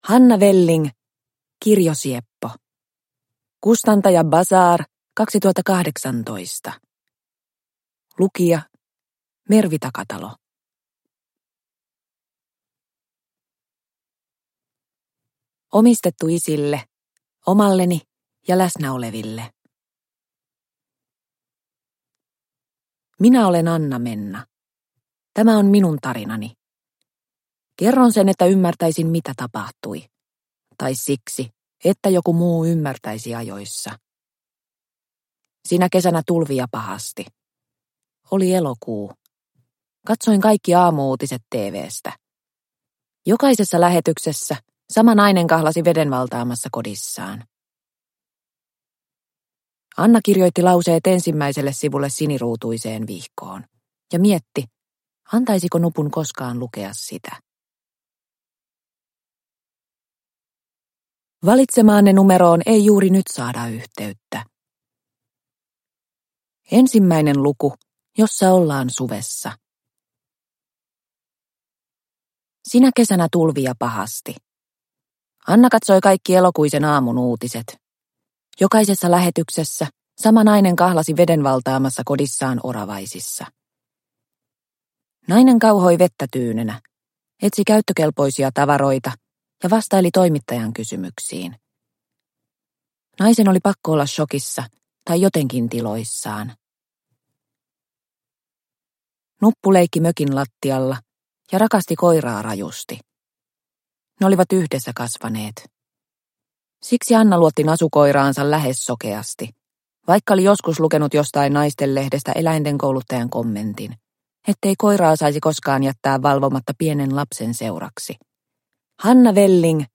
Kirjosieppo – Ljudbok – Laddas ner